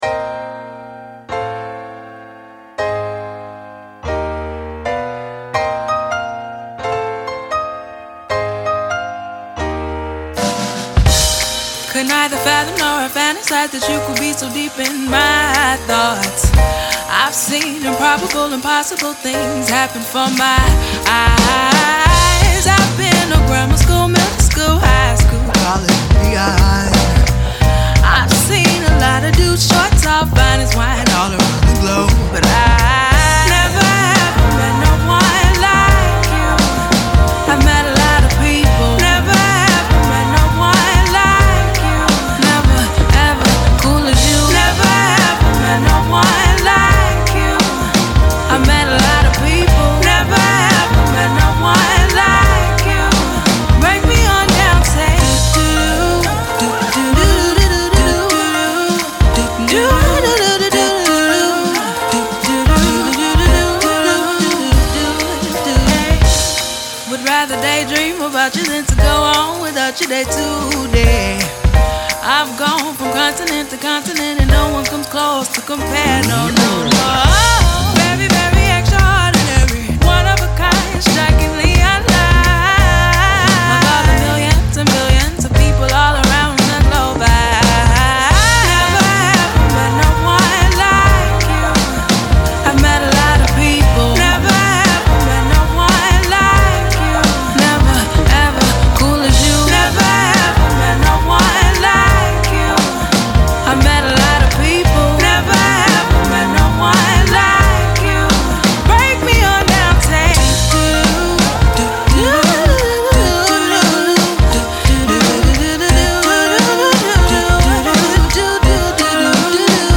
Jazz
SHE IS A HOT NEW VOCALIST WITH GREAT STYLE RANGE